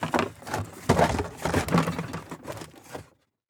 Cabinet Handle Objects Sound
household